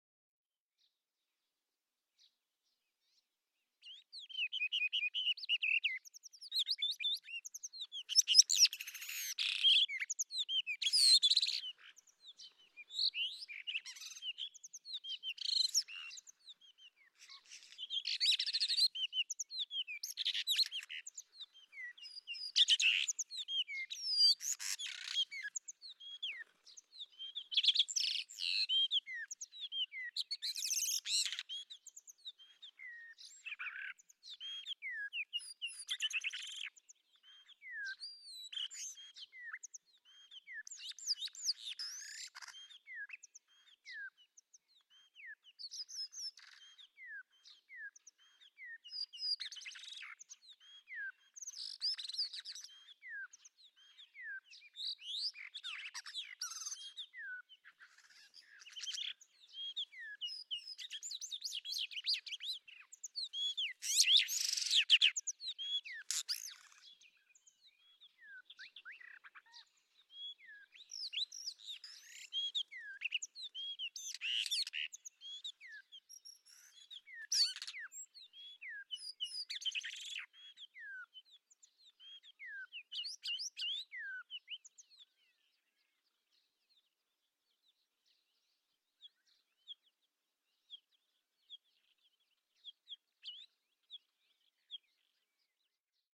PFR07769, 130523, Common Rock Thrush Monticola saxatilis, song flight,
Erdenesant, Mongolia